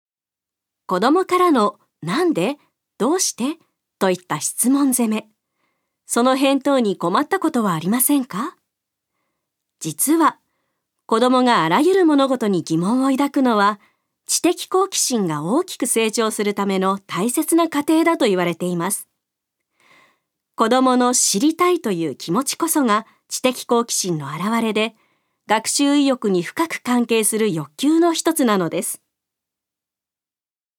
ナレーション１